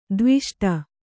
♪ dviṣṭa